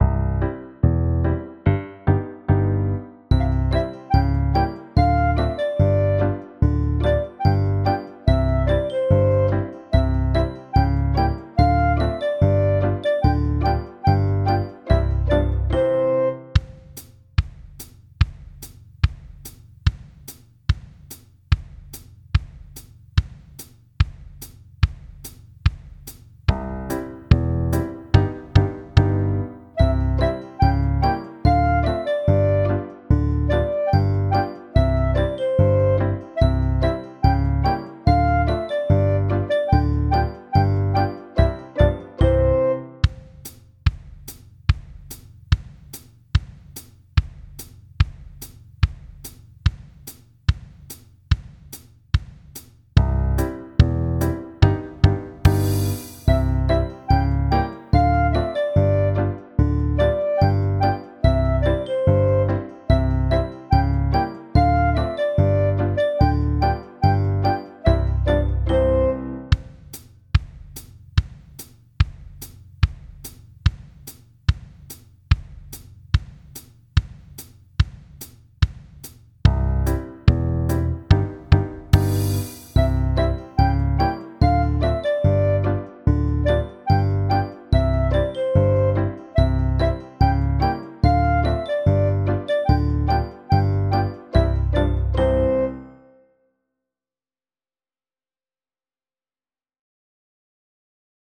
Es beginnt mit dem Refrain, der mit Stabspielen begleitet werden kann.
Die anderen Stimmen für Xylofon und Glockenspiel werden schließlich hinzugefügt.